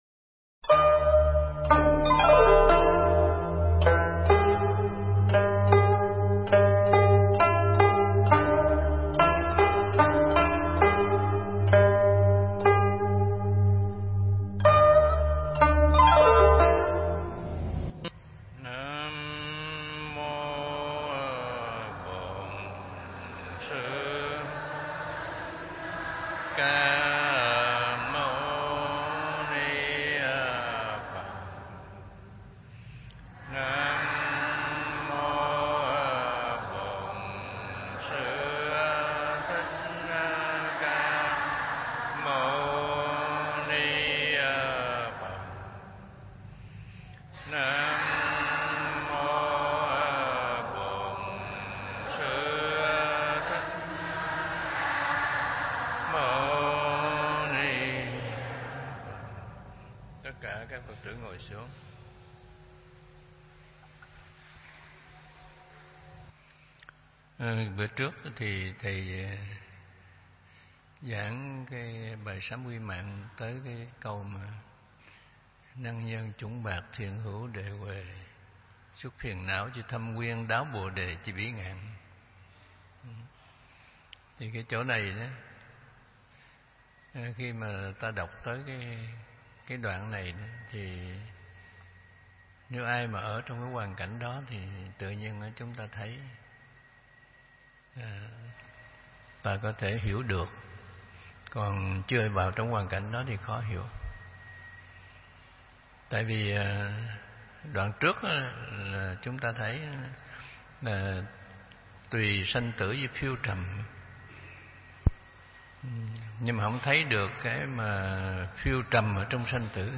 Nghe Mp3 thuyết pháp Sám Quy Mạng Phần 2 – Thích Trí Quảng
Mp3 Thuyết Pháp Sám Quy Mạng Phần 2 – Hòa Thượng Thích Trí Quảng giảng tại chùa Huê Nghiêm (Quận 2, HCM), ngày 2 tháng 4 năm 2017, (ngày 6 tháng 3 năm Đinh Hợi)